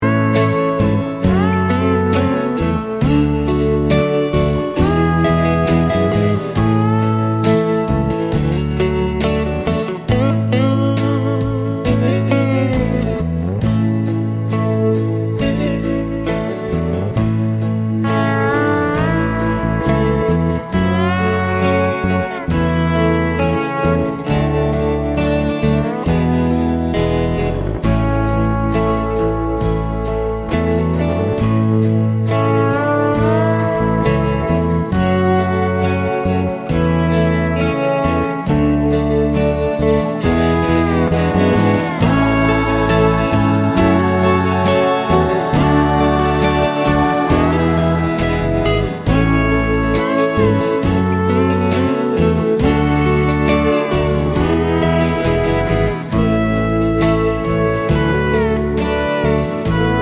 no Backing Vocals Country (Female) 2:55 Buy £1.50